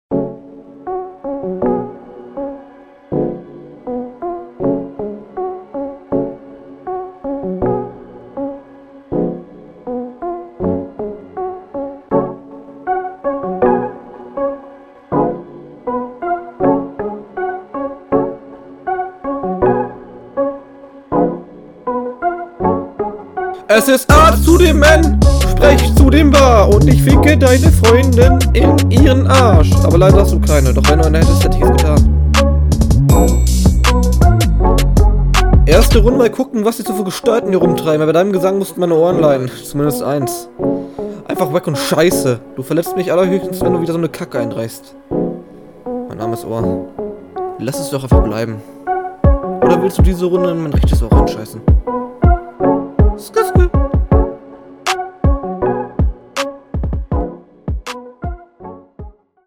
leider kein rap ^^ ganz am Anfang vllt 2 Lines.
Hallo erst mal nicer Beat aber nun zum tatsächlichen du übersteuerst sehr oft daher denke …